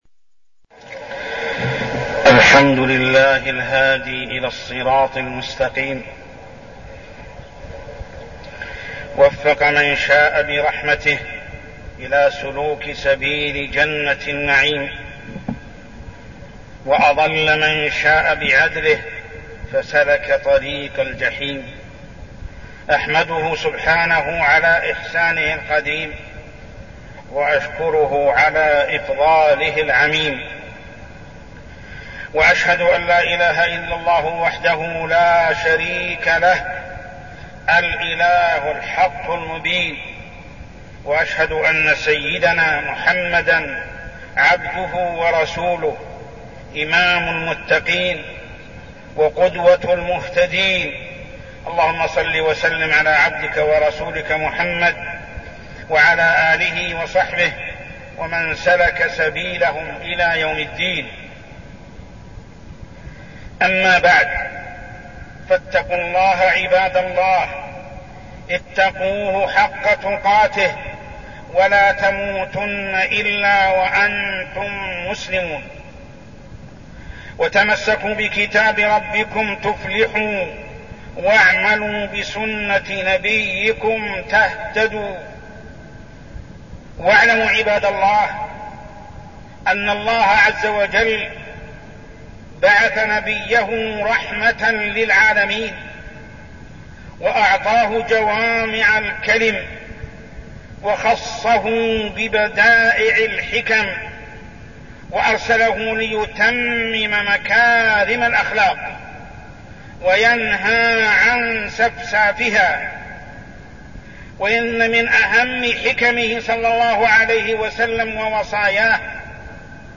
تاريخ النشر ٥ جمادى الأولى ١٤١٣ هـ المكان: المسجد الحرام الشيخ: محمد بن عبد الله السبيل محمد بن عبد الله السبيل وصايا النبي أصحابه بالتقوى